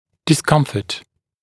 [dɪs’kʌmfət] [дис’камфэт] дискомфорт